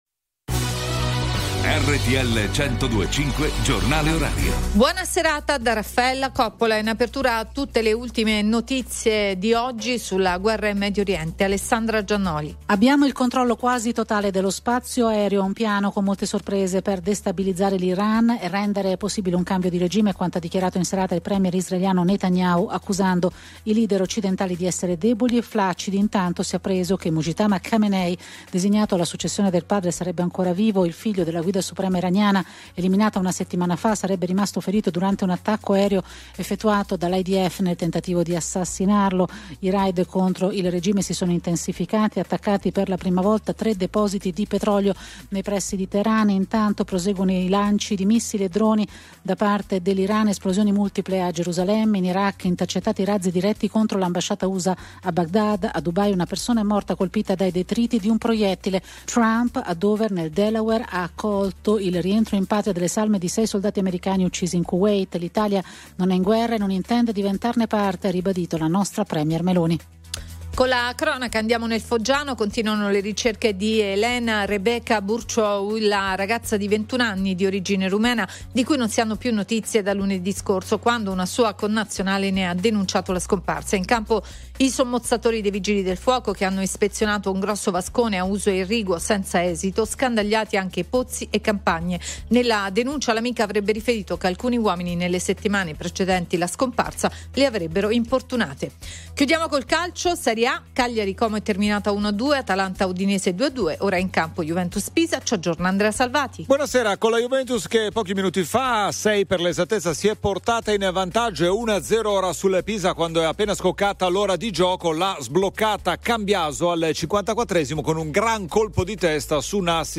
Genres: Daily News, News